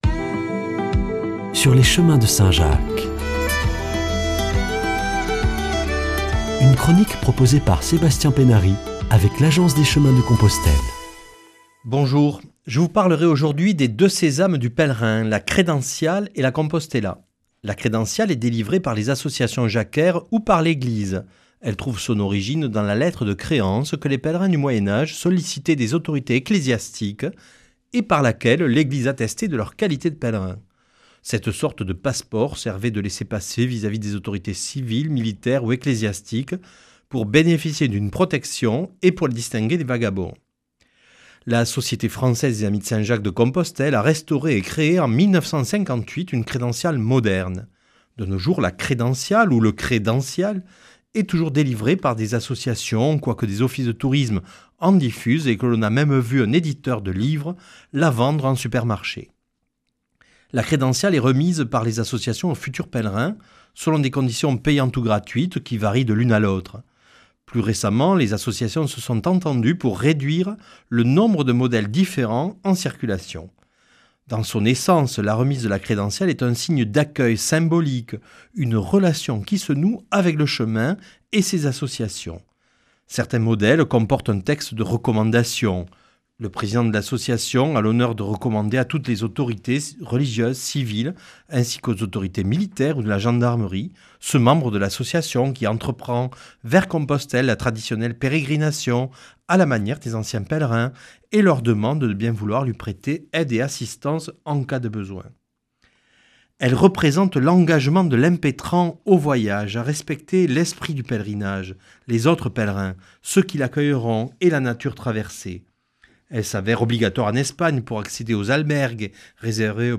[ Rediffusion ]
Présentateur